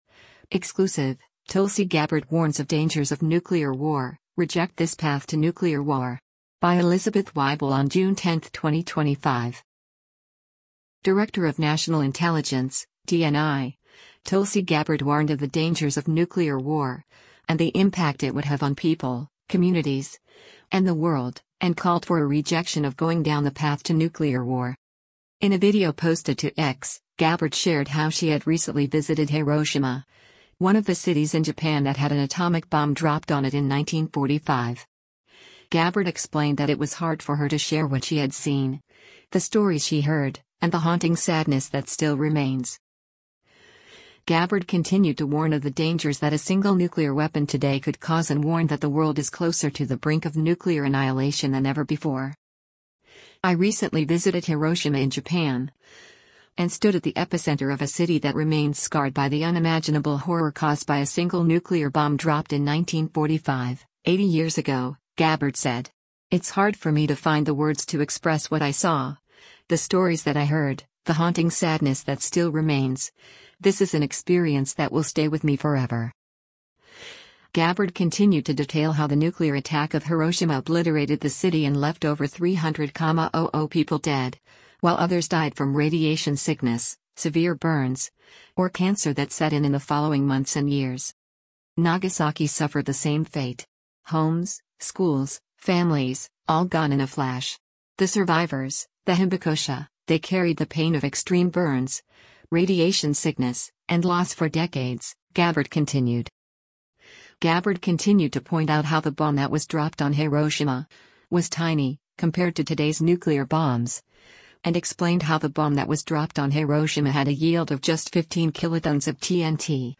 In a video posted to X, Gabbard shared how she had recently visited Hiroshima, one of the cities in Japan that had an atomic bomb dropped on it in 1945.